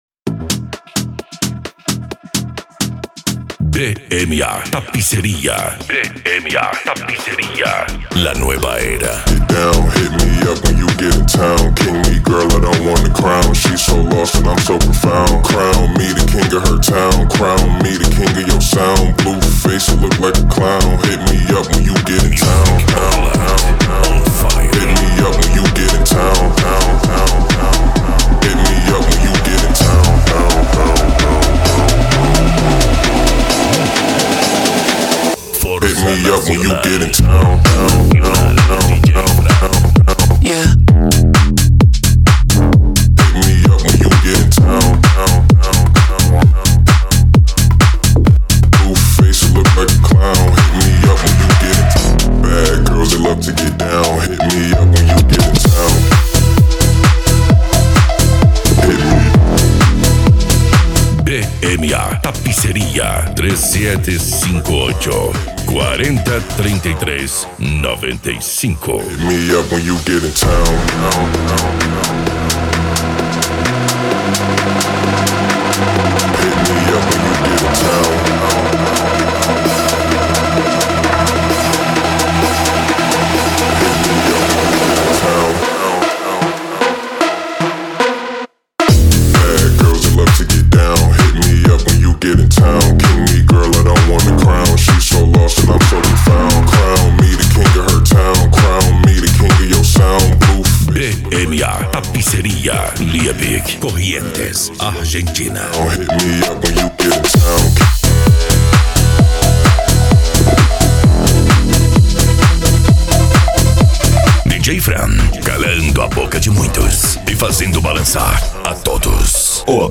Eletronica
Musica Electronica
Psy Trance